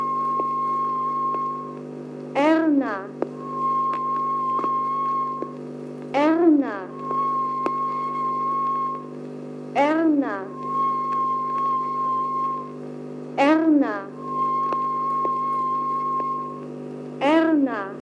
By 1943 however, it had become clear that these morse codes could easily be faked by the British, causing the planes to be guided elsewhere. 2 It was therefore decided to replace the morse code by a female voice – alternated with a 1000 Hz tone – that read the codename of the station; typically a female name like Erna or Veronica .
The cartridge (not shown here) contains a endless piece of 8 mm film, onto which the sound is recorded into a groove, much like a phonograph record. 3 The device is first described in 1995 by Herbert Jüttemann in his book Das Tefifon [5] .